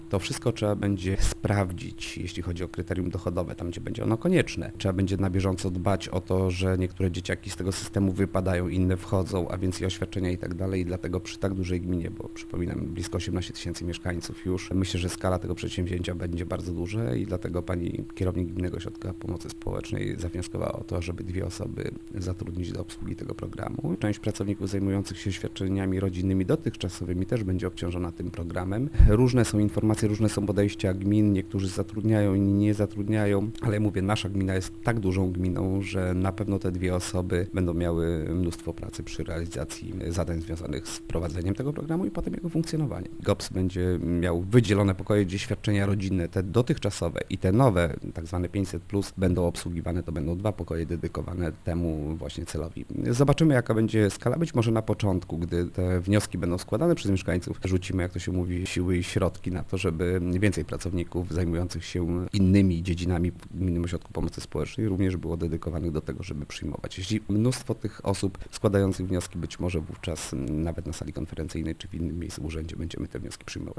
- Przyjmujemy że z nowego świadczenia skorzysta około 2 tysięcy osób, a do rozdysponowania będzie 1 milion złotych - mówi wójt gminy Łuków Mariusz Osiak: